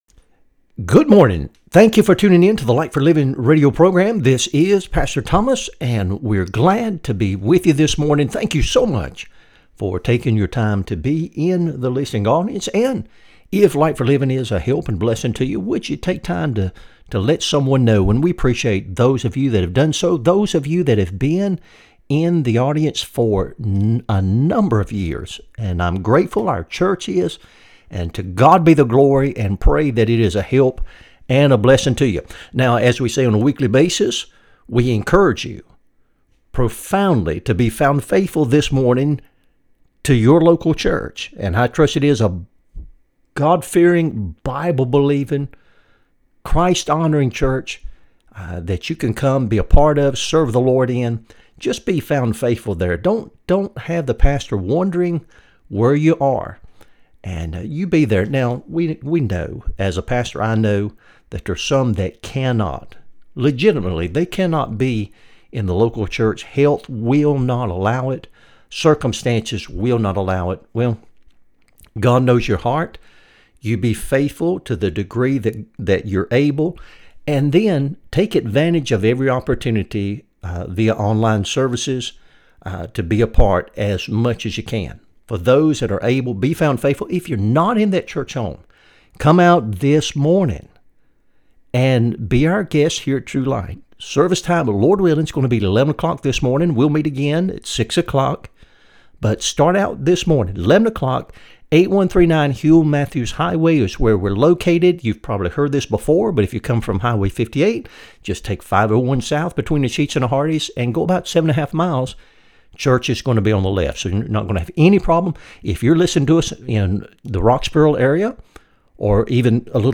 Sermons | True Light Baptist Church of Alton, Virginia
Light for Living Radio Broadcast